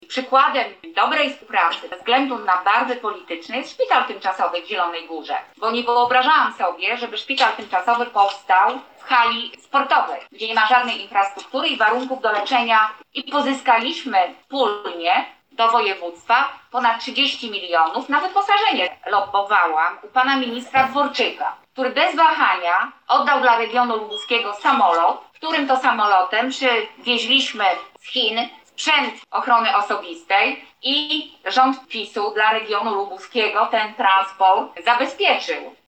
Na dzisiejszej konferencji prasowej na te zarzuty odpowiadała marszałek Elżbieta Polak.
Dziś na konferencji prasowej Elżbieta Polak odniosła się do tych zarzutów.